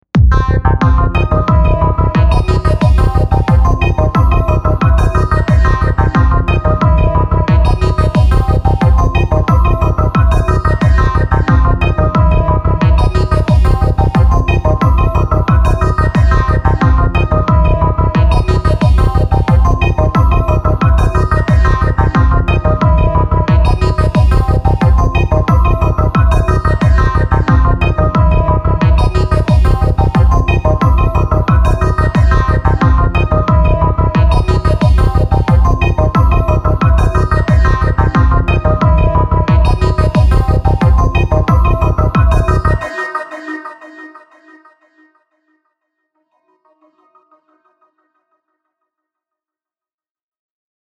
Очередной опыт с прямым битом
Захотелось мне чего-то прямого, монотонного, но вязкого и, если можно так сказать, тяжёлого, но без обилия обычных с моей стороны экспериментальных пристрастий ... Требуется ваш непредвзятый взгляд - нормально ли лёг бас в следующем фрагменте?